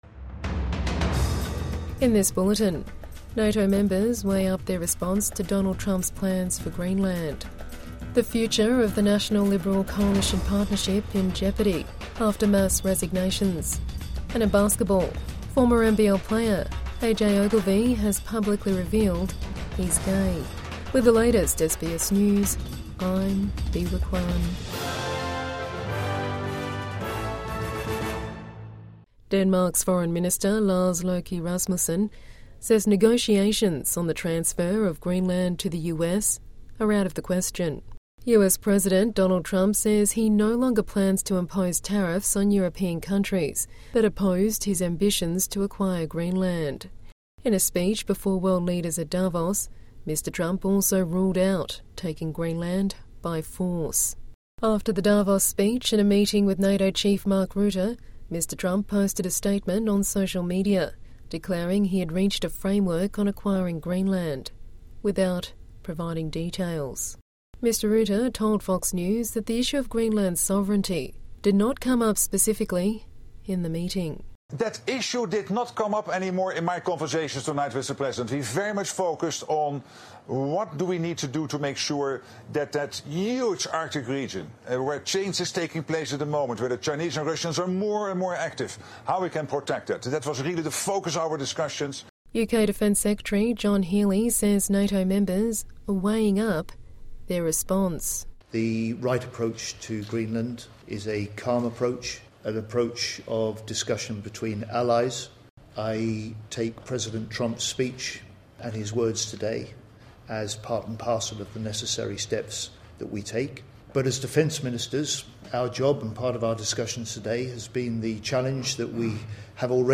NATO members weigh up response to Trump's plans for Greenland | Midday News Bulletin 22 January 2026